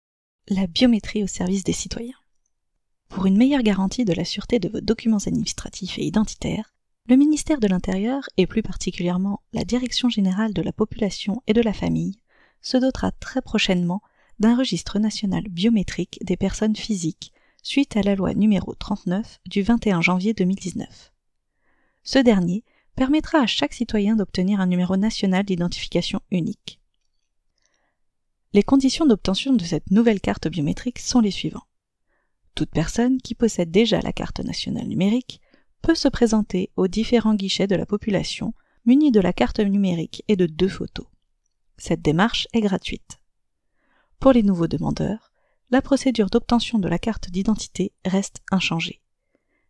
外籍美式英语配音
配音风格： 轻松 稳重